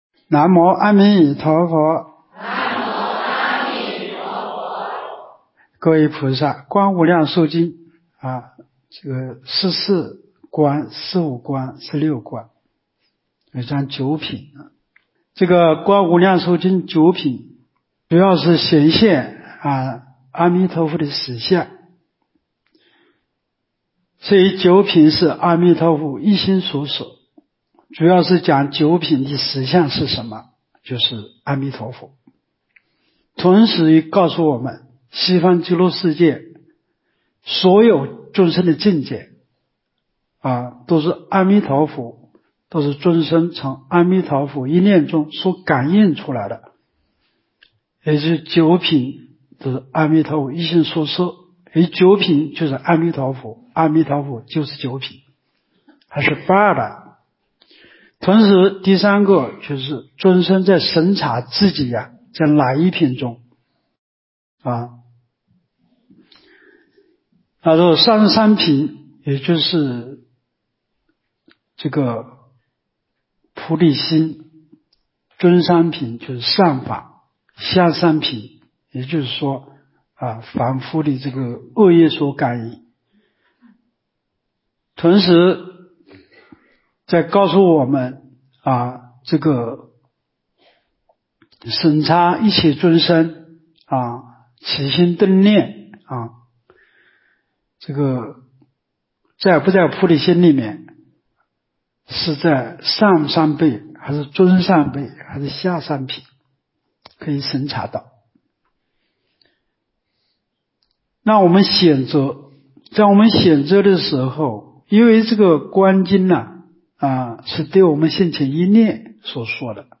无量寿寺冬季极乐法会精进佛七开示（35）（观无量寿佛经）...